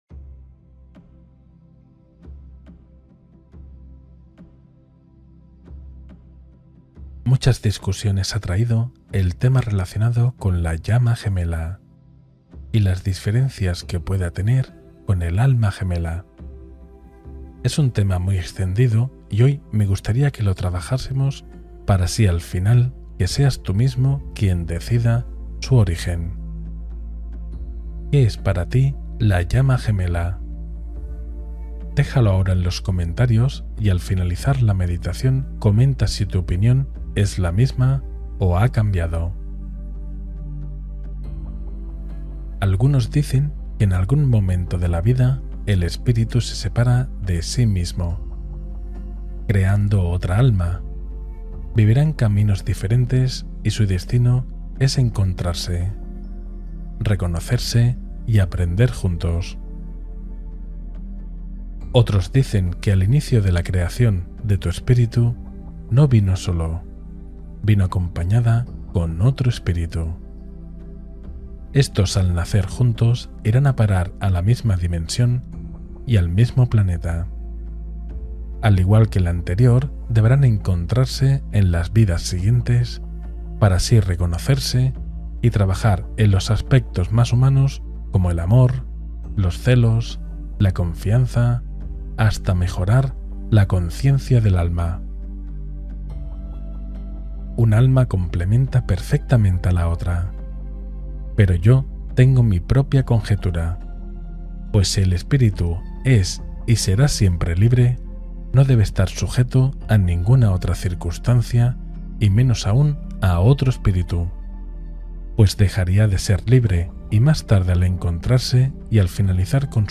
Conoce a tu Llama Gemela con esta Meditación Profunda